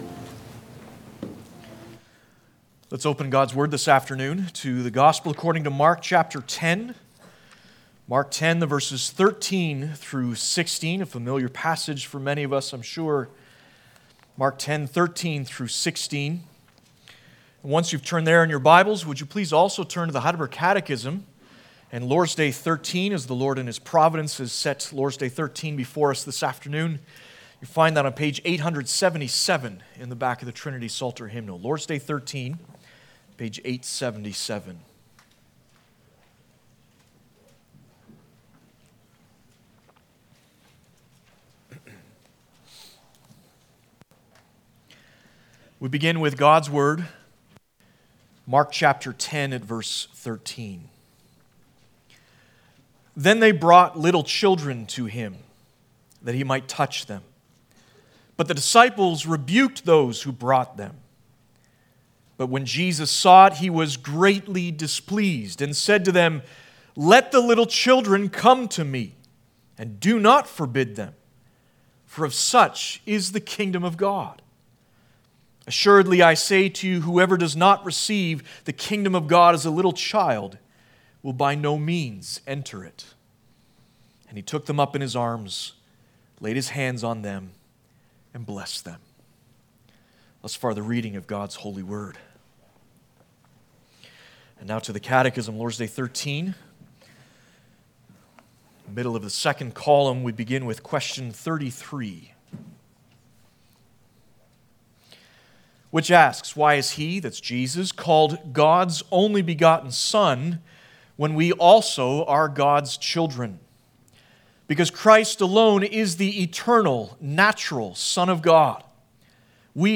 Passage: Mark10:13-16 Service Type: Sunday Afternoon